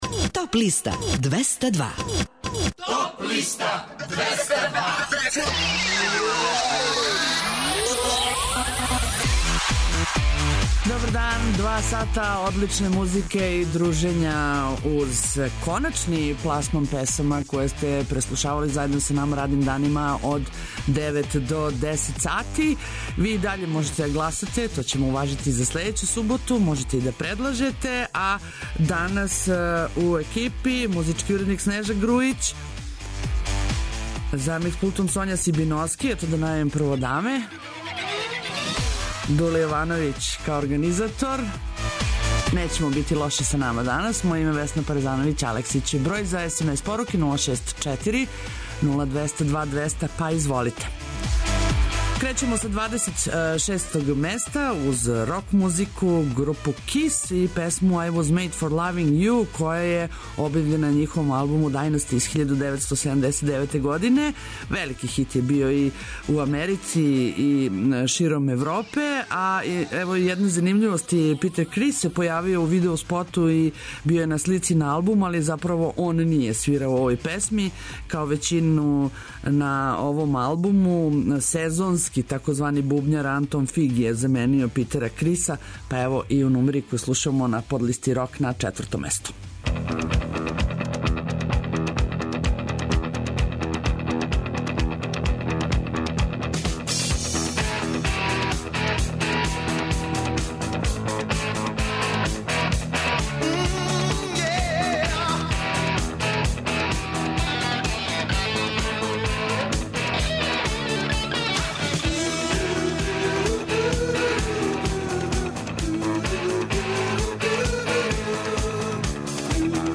У овонедељном издању Топ листе у 2016. години слушајте новогодишње и божићне музичке нумере, иностране и домаће новитете, као и композиције које су се нашле на подлисти лектира, класика, етно, филмскe музикe...